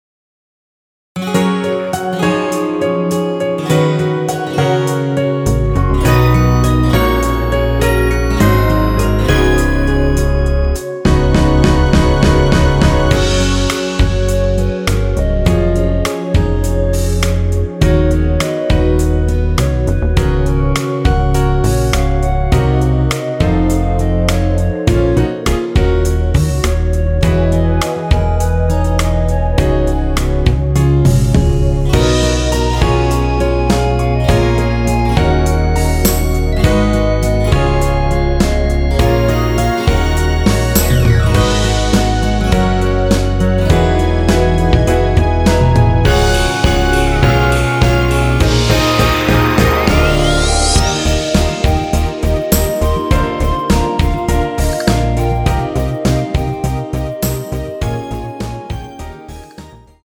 원키에서(+4)올린 멜로디 포함된 MR입니다.(미리듣기 확인)
F#
앞부분30초, 뒷부분30초씩 편집해서 올려 드리고 있습니다.
중간에 음이 끈어지고 다시 나오는 이유는